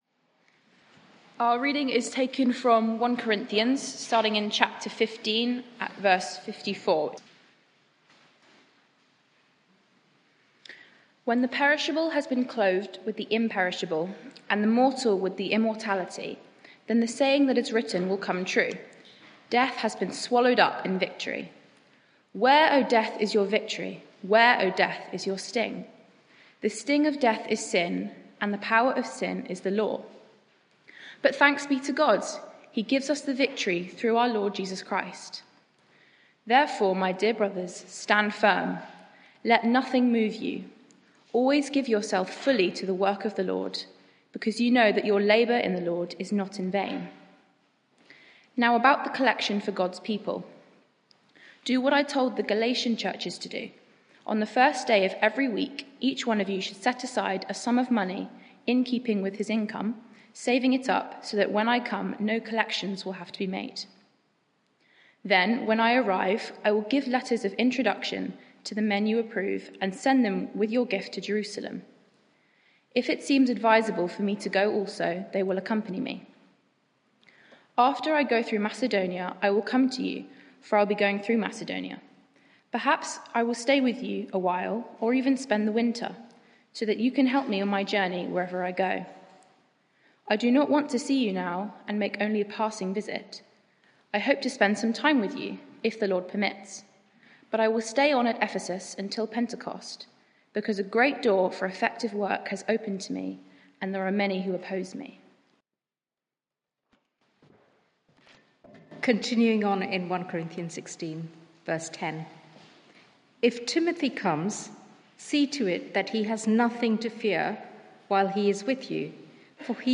Media for 6:30pm Service on Sun 30th Apr 2023 18:30 Speaker
Sermon